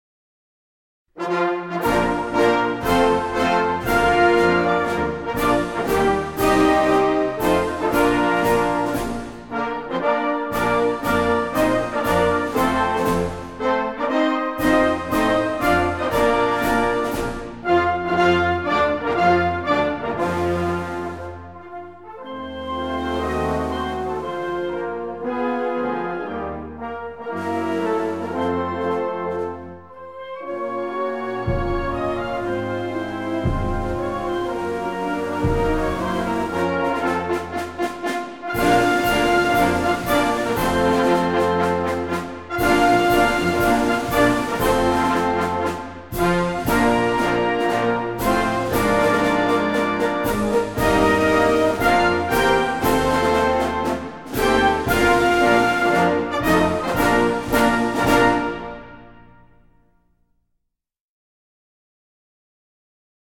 music only   with lyrics    PowerPoint